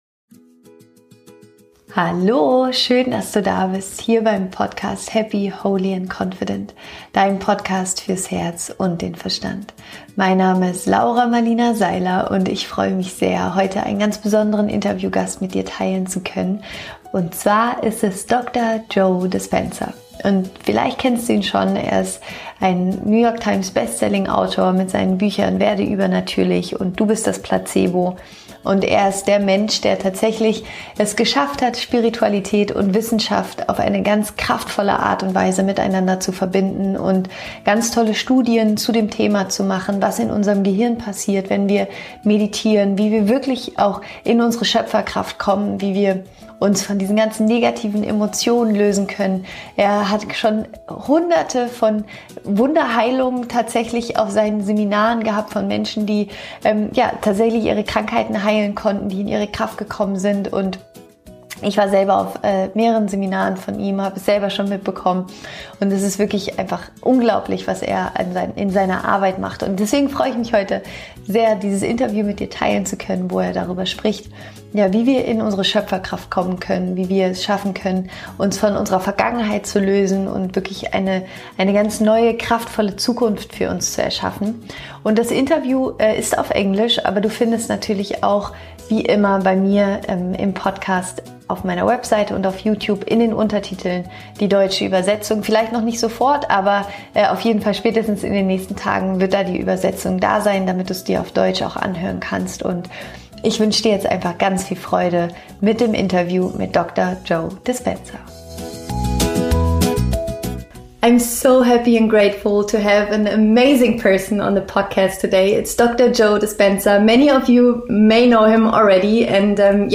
Ich freue mich riesig heute einen ganz besonderen Gast im Interview zu haben: Dr. Joe Dispenza.